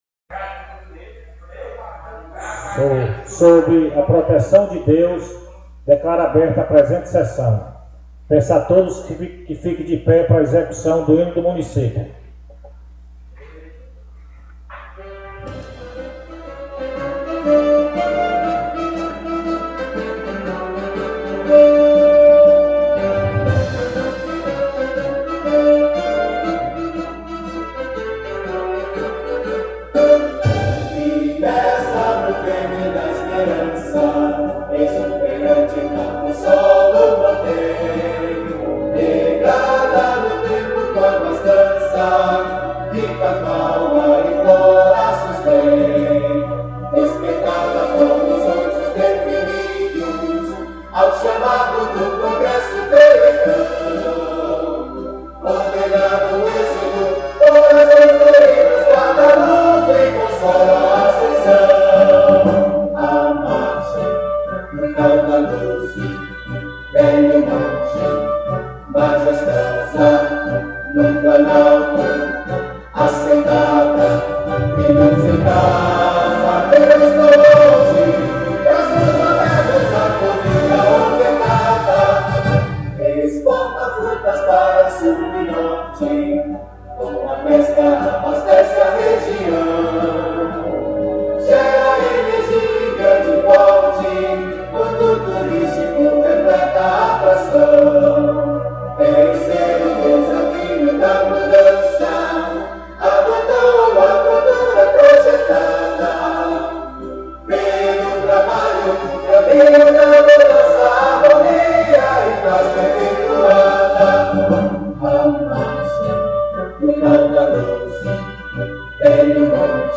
Mídias Sociais 6ª SESSÃO ORDINÁRIA PLENÁRIA 13 de abril de 2026 áudio de sessões anteriores Rádio Câmara A Sessão da Câmara de Vereadores ocorre na segunda-feira, a partir das 19:30h.